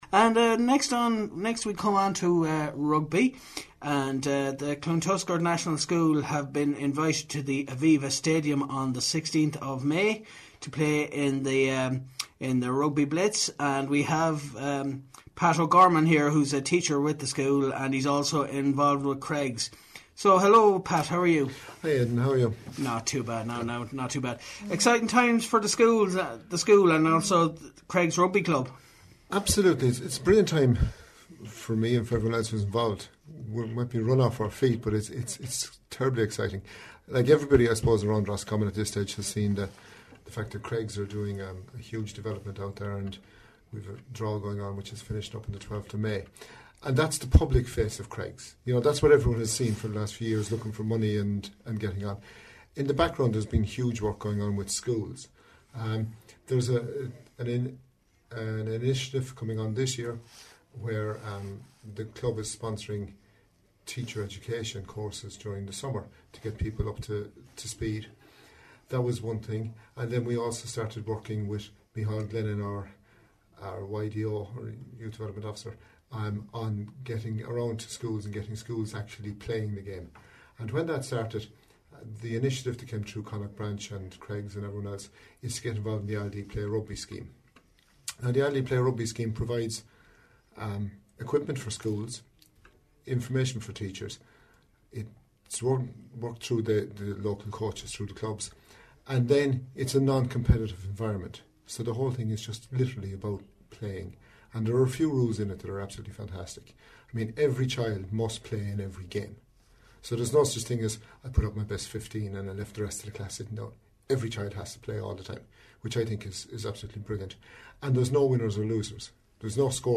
Interview - RosFM 94.6